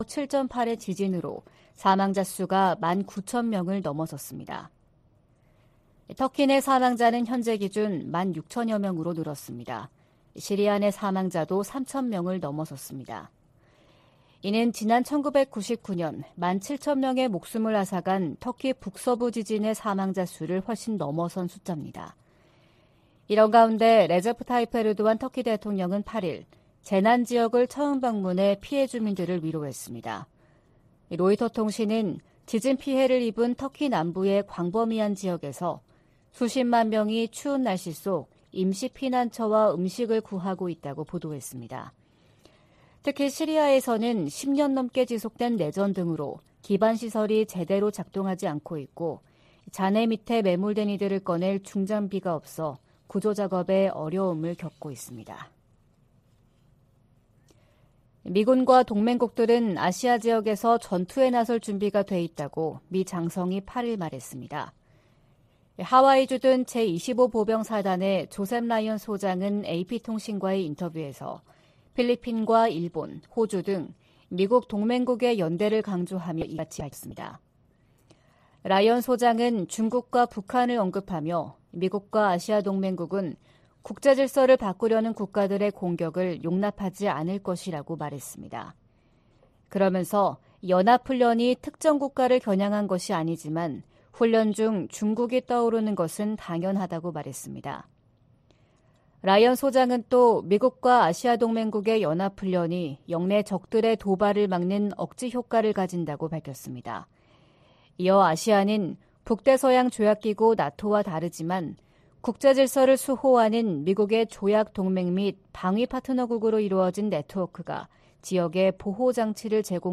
VOA 한국어 '출발 뉴스 쇼', 2023년 2월 10일 방송입니다. 북한 건군절 기념 열병식에서 고체연료 대륙간탄도 미사일, ICBM으로 추정되는 신형 무기가 등장했습니다. 북한의 핵・미사일 관련 조직으로 추정되는 미사일총국이 공개된 데 대해 미국 정부는 북한 미사일 개발을 억지하겠다는 의지를 확인했습니다.